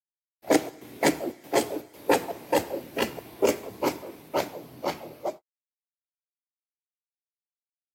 Tiếng Kéo cắt, xén Vải (Sample 2)
Thể loại: Tiếng động
Description: Tiếng kéo cắt vải, hay còn gọi là âm thanh kéo cắt, tiếng xén vải, Âm thanh này được tạo ra khi lưỡi kéo di chuyển qua vải...
tieng-keo-cat-xen-vai-sample-2-www_tiengdong_com.mp3